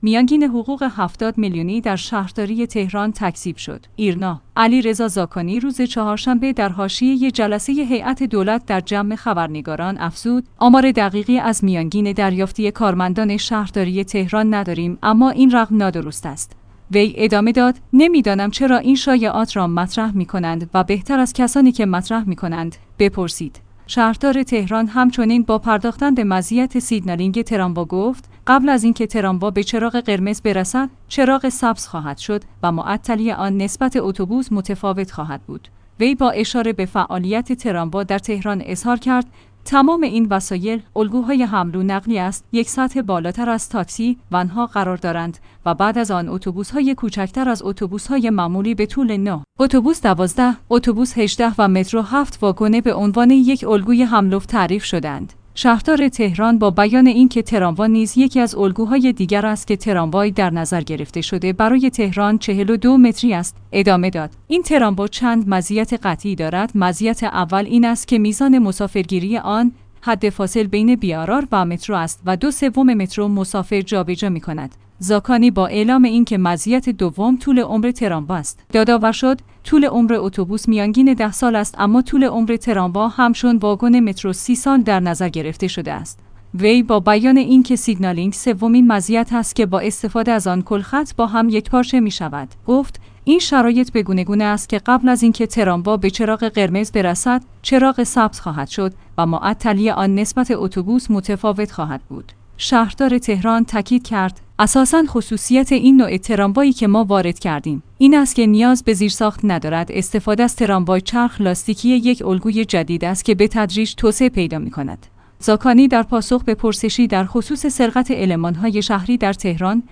ایرنا/ علیرضا زاکانی روز چهارشنبه در حاشیه جلسه هیات دولت در جمع خبرنگاران افزود: آمار دقیقی از میانگین دریافتی کارمندان شهرداری تهران نداریم اما این رقم نادرست است.